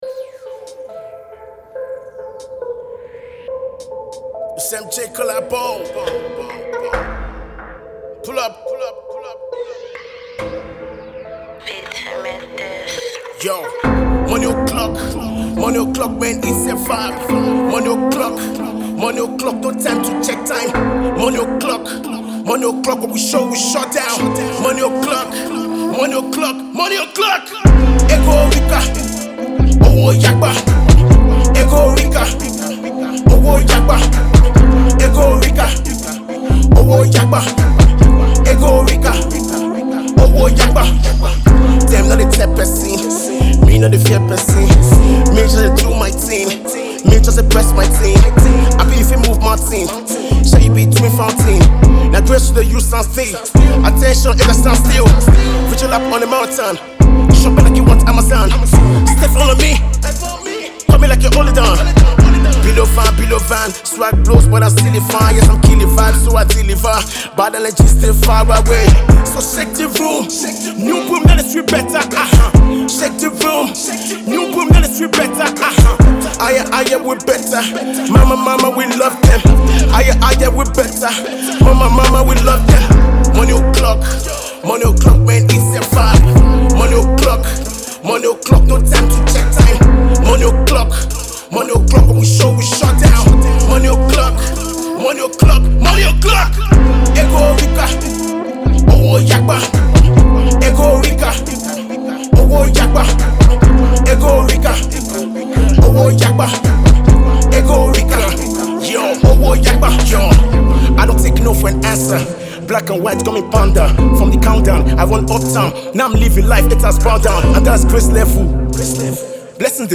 Hiphop and Trap banger